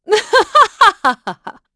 Isolet-Vox_Happy4.wav